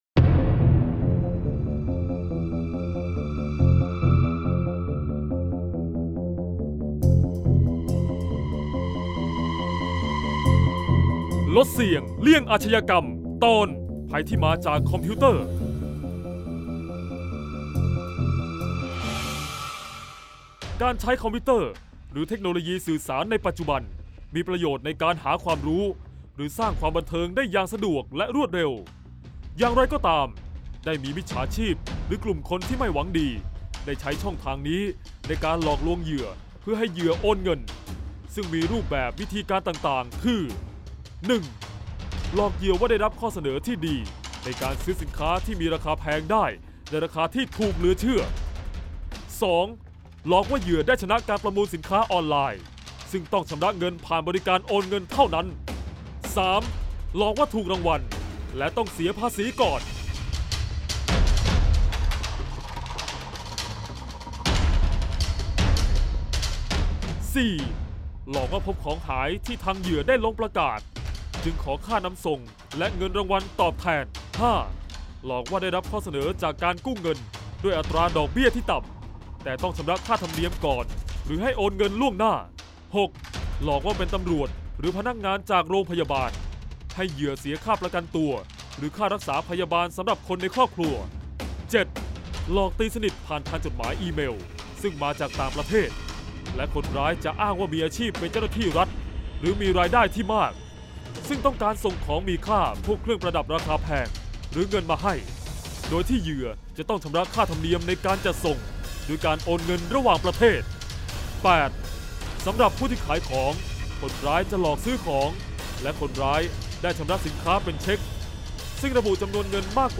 เสียงบรรยาย ลดเสี่ยงเลี่ยงอาชญากรรม 42-ภัยที่มาจากคอมพิวเตอร์